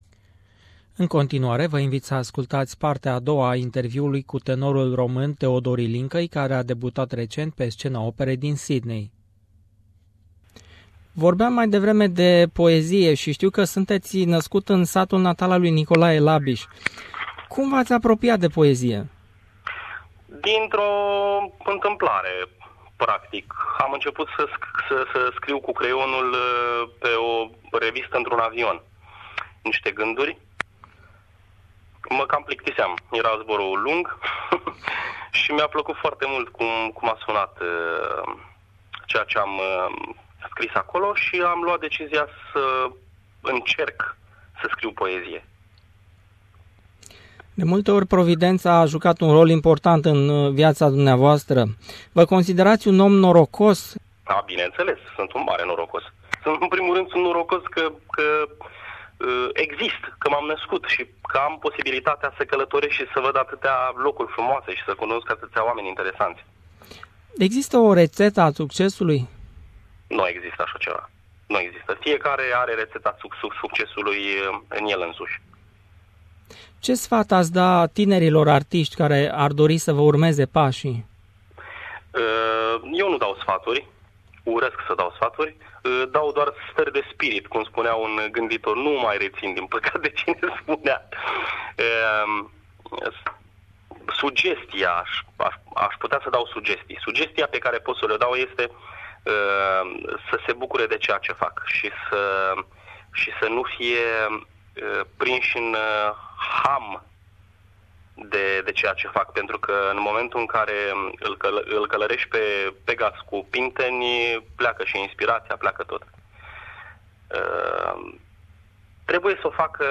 Interviu cu tenorul roman Teodor Ilincai (ultima parte)